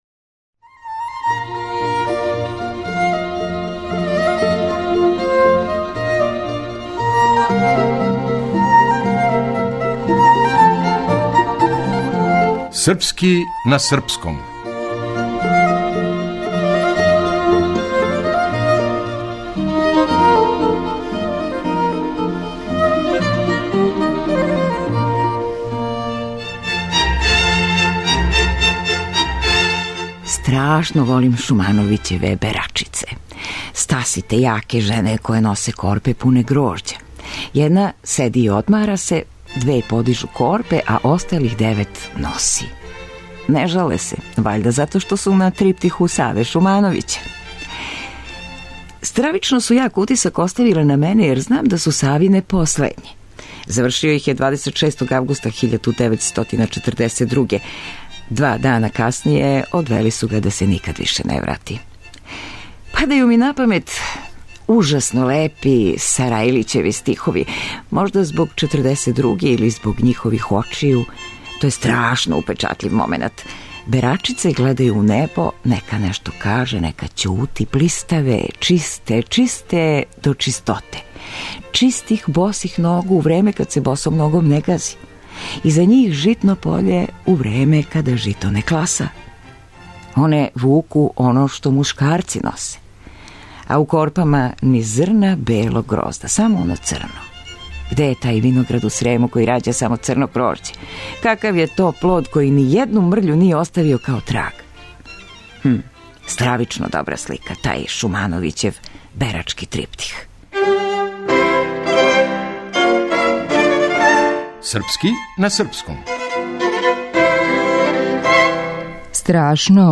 Драмска уметница: